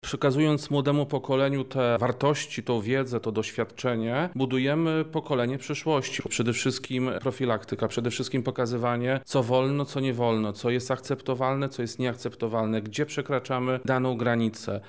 Dziś (02.10) w Szkole Podstawowej nr 2 im. Jana Kochanowskiego w Lublinie odbyła się uroczysta inauguracja kampanii społecznej „Dzieciństwo bez przemocy”, w której wzięli udział przedstawiciele Centrum Interwencji Kryzysowej oraz stowarzyszenia Sempre a Frente.